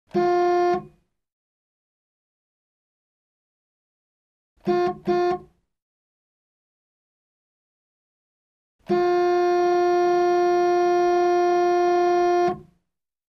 Звуки сигнала машины
На этой странице собраны разнообразные звуки автомобильных сигналов: от стандартных гудков до экстренных клаксонов.